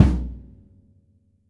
BD2 VEL2.wav